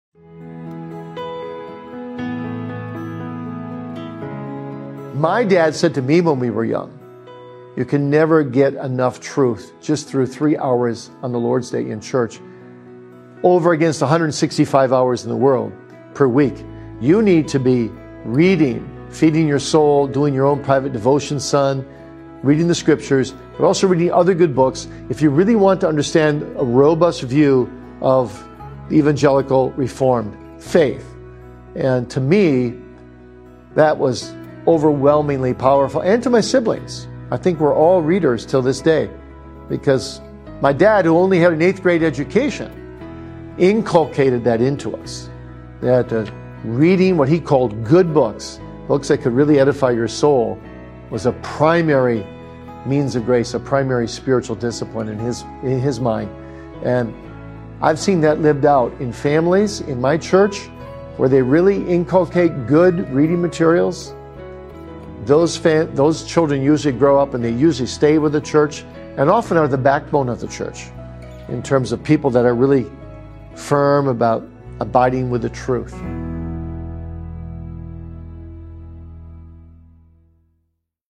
Life Story & Testimony